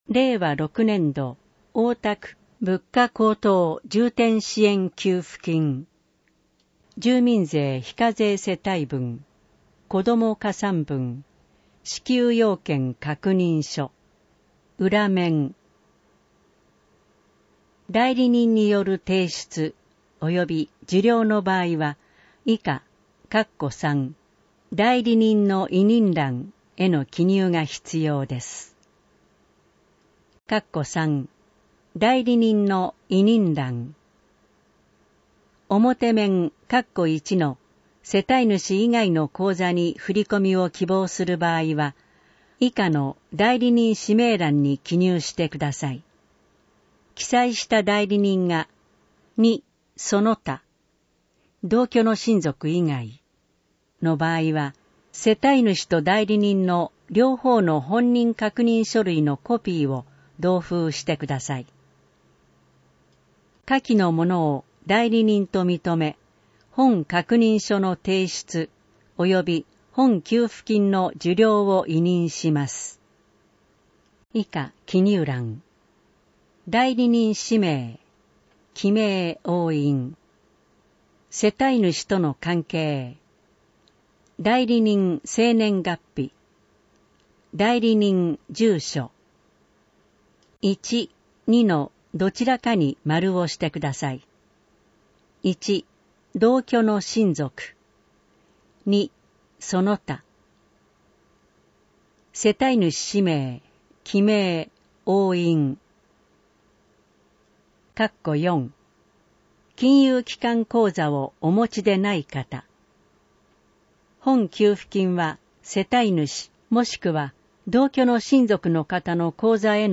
なお、この音声版は、障がい者総合サポートセンター声の図書室で製作したCDを再生したものです。